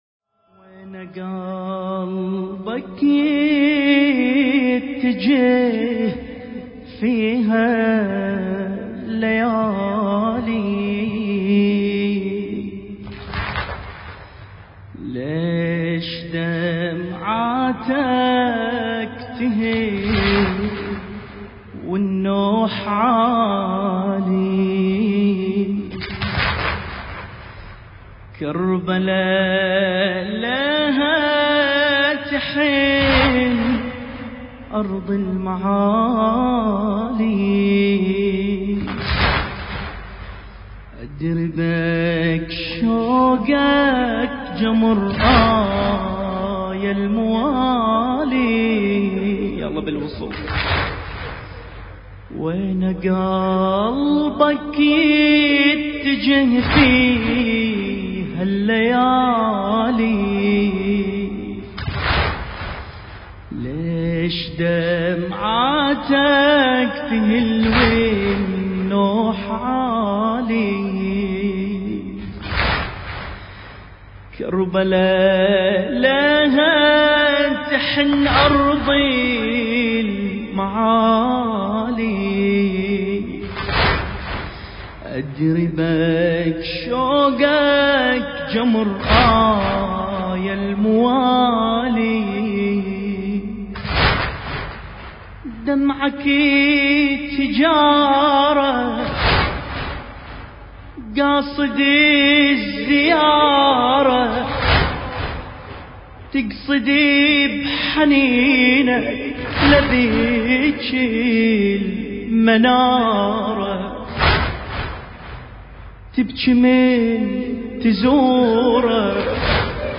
المراثي
الحجم 3.60 MB الشاعر: محمود القلاف المكان: هيئة صاحب الزمان (عجّل الله فرجه) الكويت- ليلة 11 صفر الخير التاريخ: 1440 للهجرة